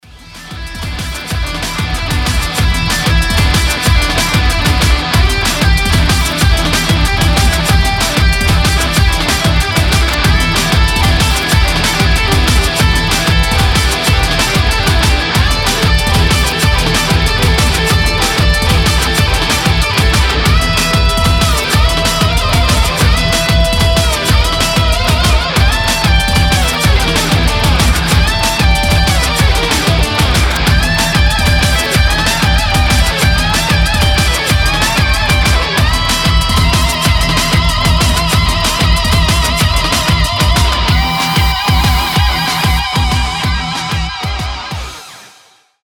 • Качество: 320, Stereo
громкие
мощные
энергичные
быстрые
Industrial metal
Мощное гитарное соло